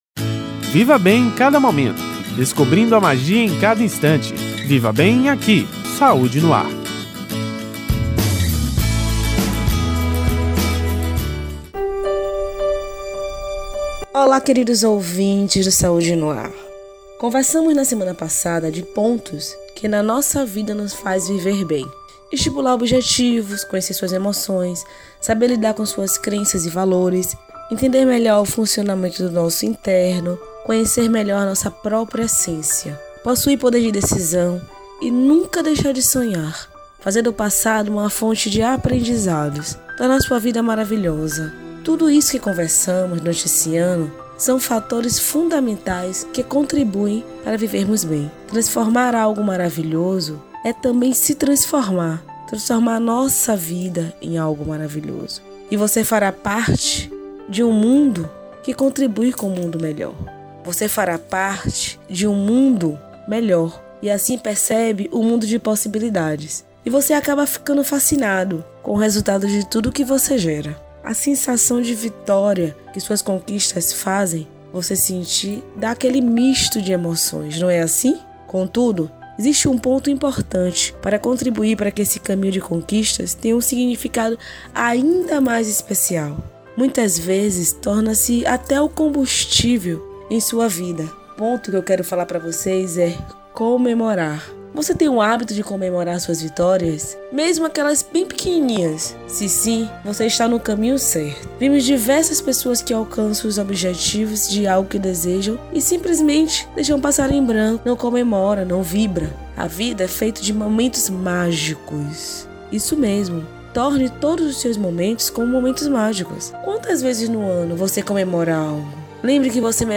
O assunto foi tema do Quadro: “Viver Bem” exibido toda sexta-feira no Programa Saúde no ar, veiculado pela Rede Excelsior de Comunicação: AM 840, FM 106.01, Recôncavo AM 1460 e Rádio Saúde no ar / Web.